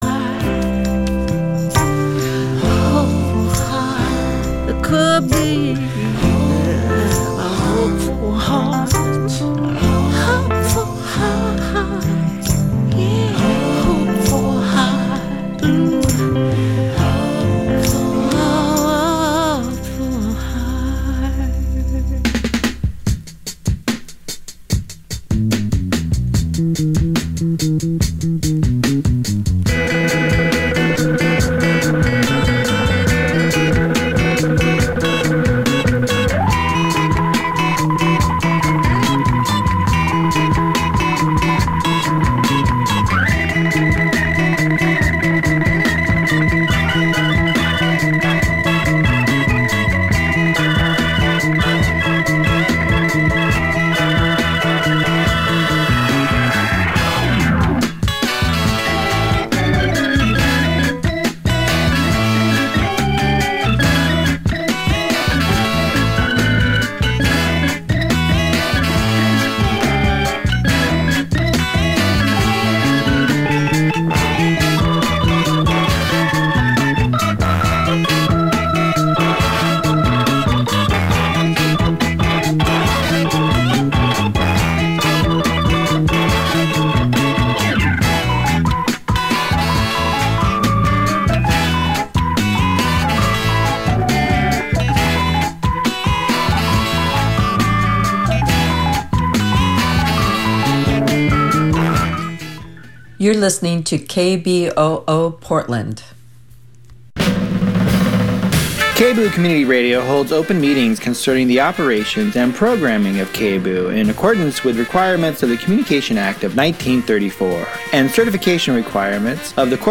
First Voices Radio, now in its 30th year on the air, is an internationally syndicated one-hour radio program originating from and heard weekly on Radio Kingston WKNY 1490 AM and 107.9 FM in Kingston, New York.